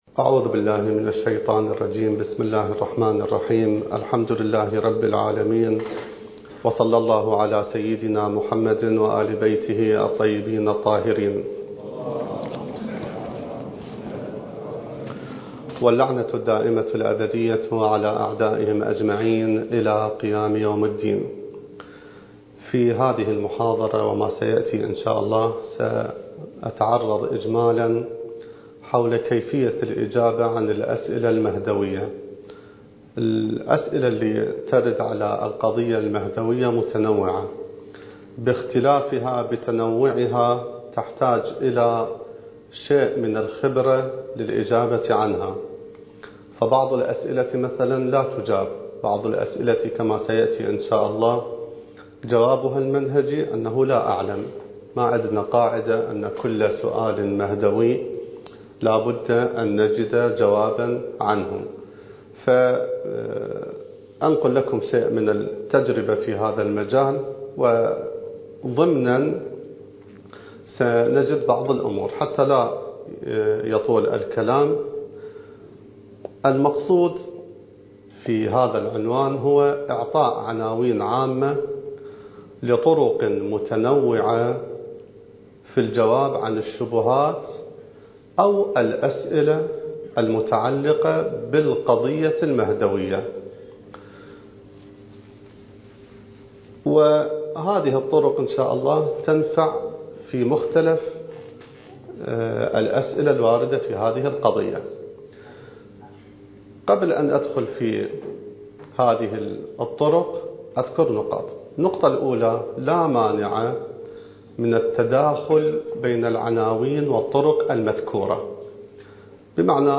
الدورة المهدوية الأولى المكثفة (المحاضرة الثانية والثلاثون)
المكان: النجف الأشرف